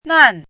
chinese-voice - 汉字语音库
nan4.mp3